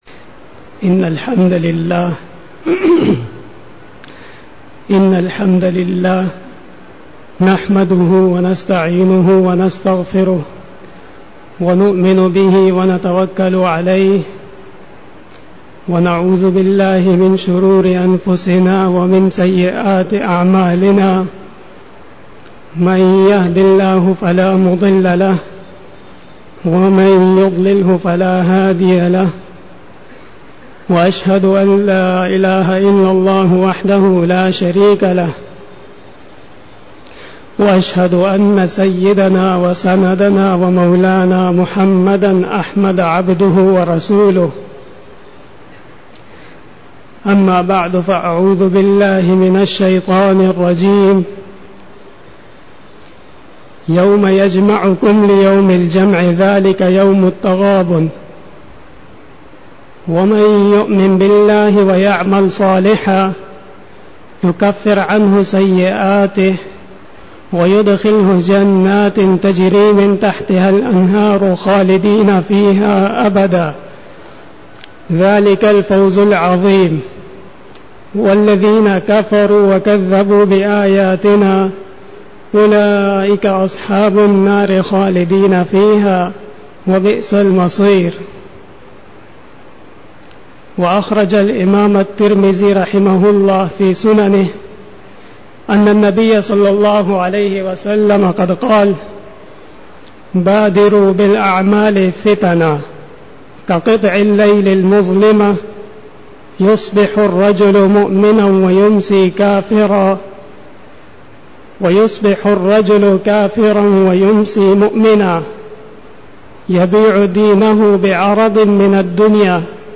25_Bayan e Juma tul Mubarak 28-june- 2013
Khitab-e-Jummah 2013